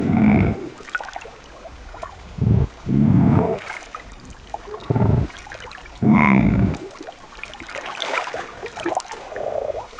sound-effect-generation text-to-audio